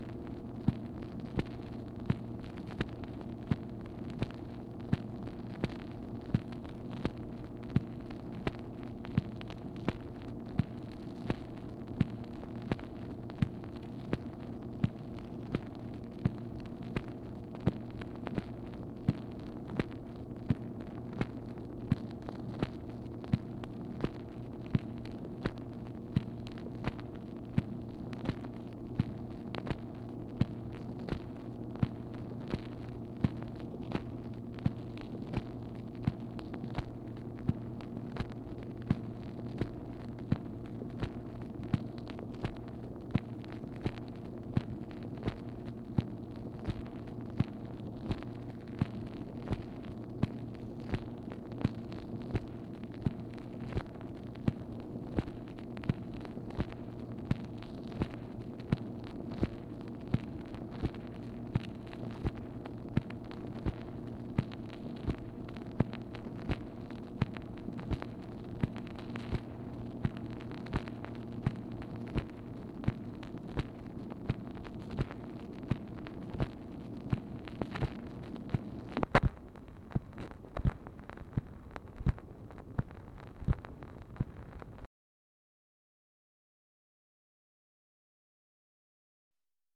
MACHINE NOISE, March 17, 1964
Secret White House Tapes | Lyndon B. Johnson Presidency